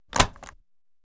lock.mp3